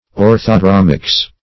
orthodromics - definition of orthodromics - synonyms, pronunciation, spelling from Free Dictionary
\Or`tho*drom"ics\